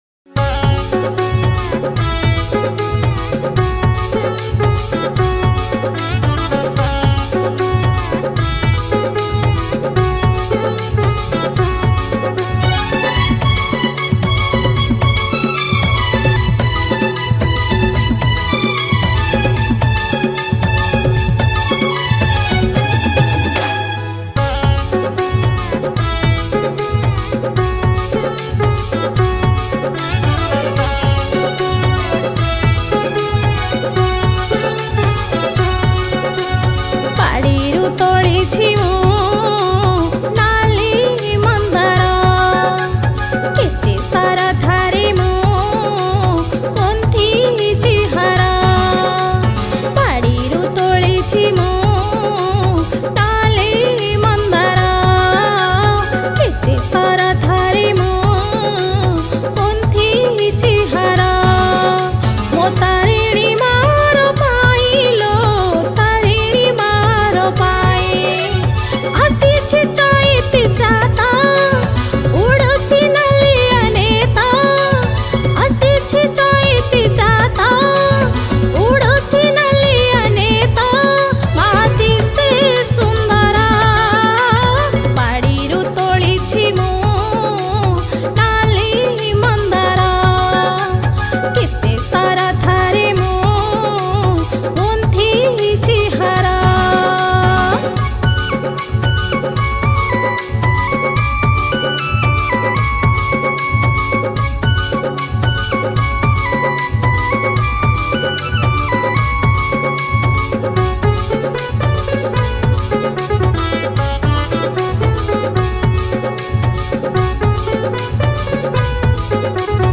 Odia New Bhajan 2026